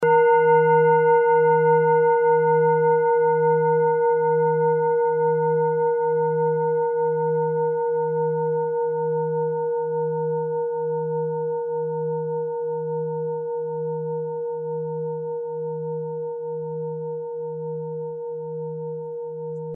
Klangschale Nr.16 Bengalen
Diese Klangschale ist eine Handarbeit aus Bengalen. Sie ist neu und wurde gezielt nach altem 7-Metalle-Rezept in Handarbeit gezogen und gehämmert.
Hörprobe der Klangschale
Filzklöppel oder Gummikernschlegel
Diese Frequenz kann bei 160Hz hörbar gemacht werden; das ist in unserer Tonleiter nahe beim "E".